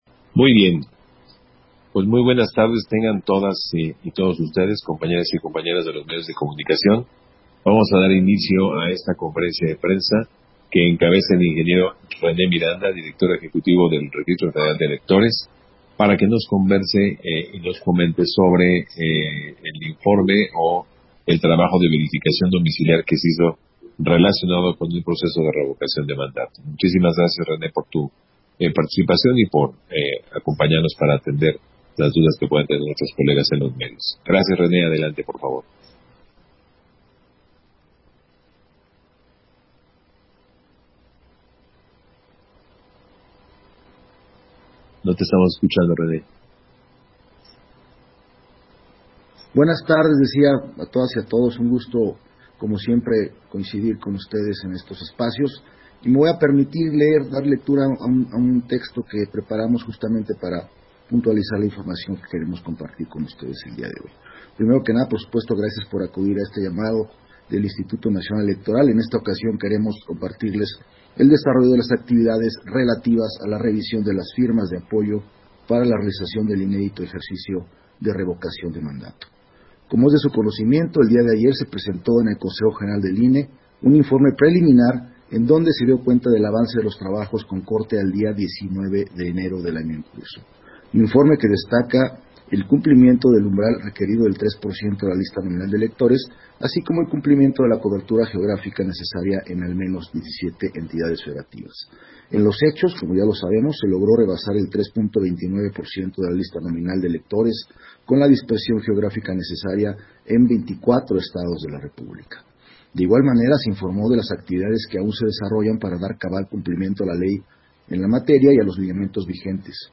270122_AUDIO_CONFERENCIA-DE-PRENSA